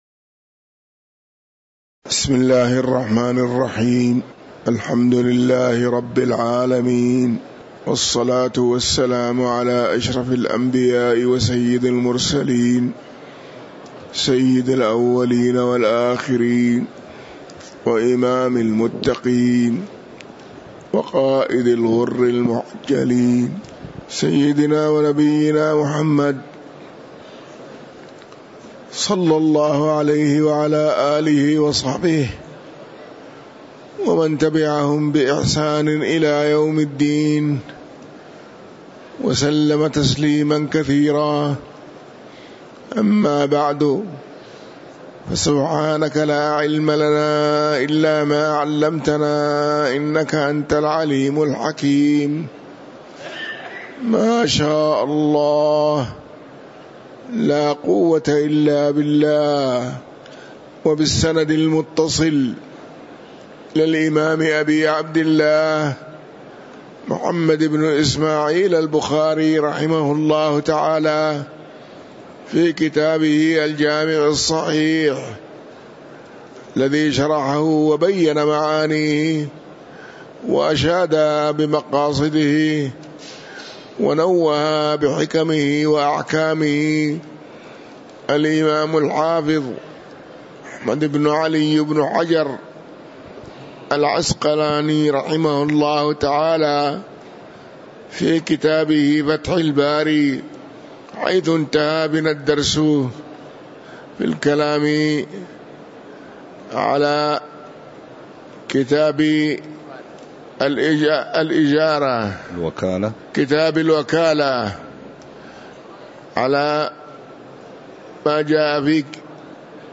تاريخ النشر ٦ جمادى الآخرة ١٤٤٥ هـ المكان: المسجد النبوي الشيخ